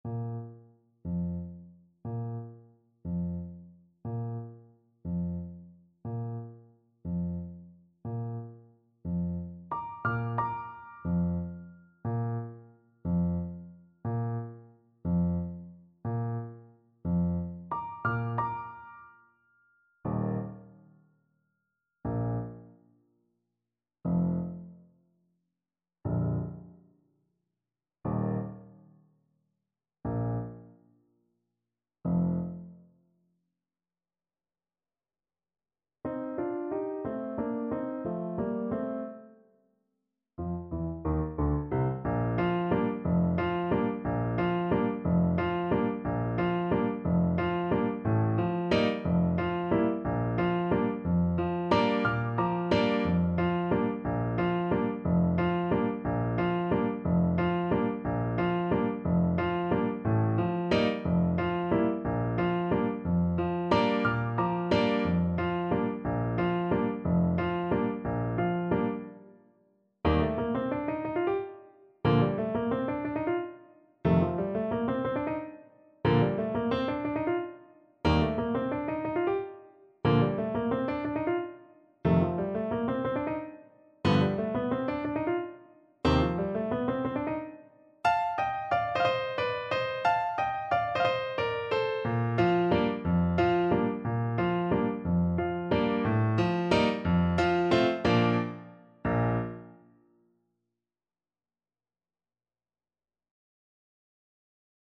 Bb major (Sounding Pitch) C major (Clarinet in Bb) (View more Bb major Music for Clarinet )
6/8 (View more 6/8 Music)
Pochissimo pi mosso = 144 . =60
Classical (View more Classical Clarinet Music)